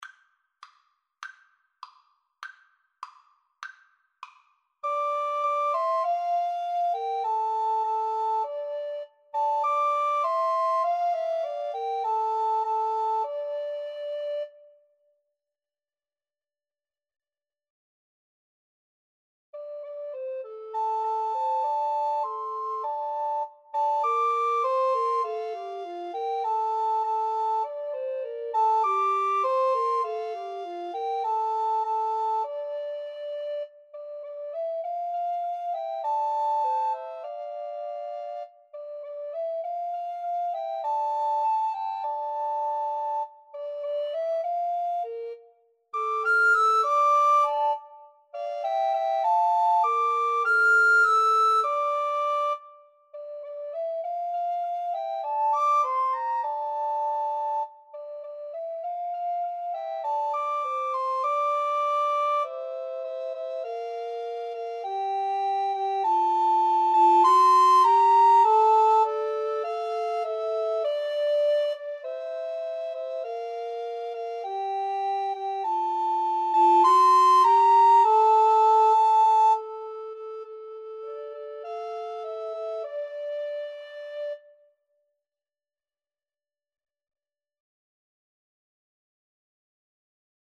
D minor (Sounding Pitch) (View more D minor Music for Recorder Trio )
Moderato
Recorder Trio  (View more Intermediate Recorder Trio Music)
Classical (View more Classical Recorder Trio Music)